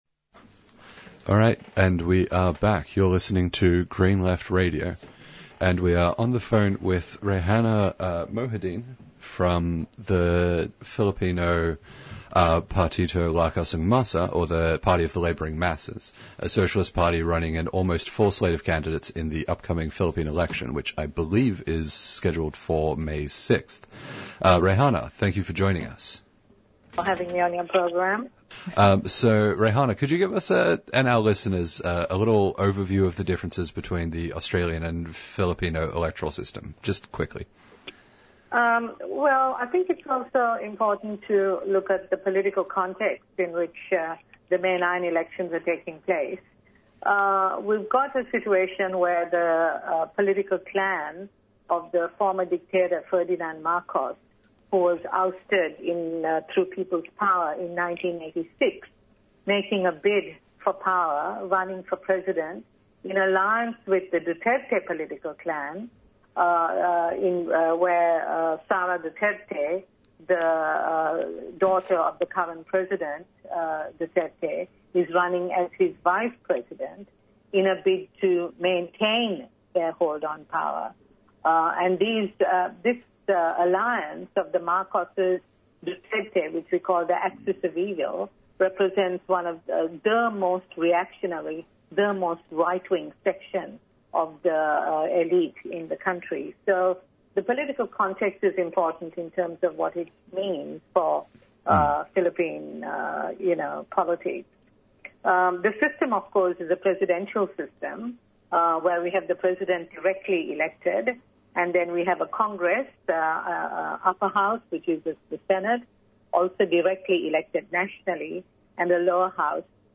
Green Left news discussed by the presenters.
Interviews and Discussion